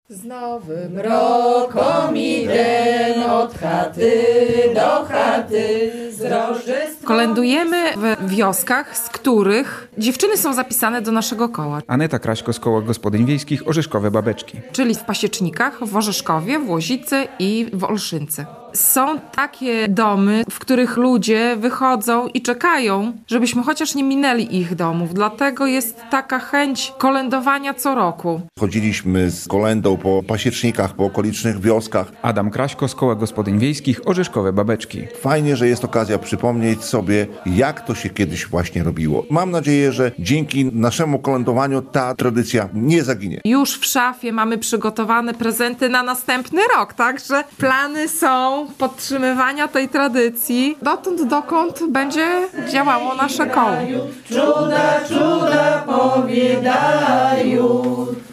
Koło Gospodyń Wiejskich "Orzeszkowe Babeczki" z okolic Hajnówki odwiedza mieszkańców pobliskich wsi. Kolędnicy mają ze sobą tradycyjną gwiazdę i śpiewają prawosławne kolędy.